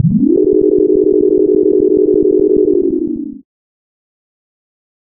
snd_elevator.wav